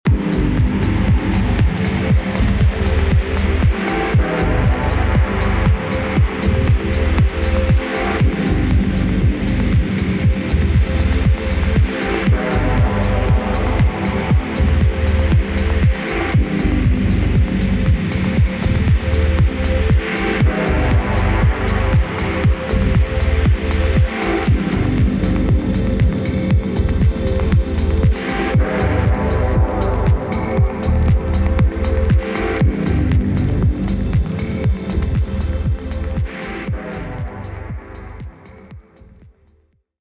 Género: House / Deep House.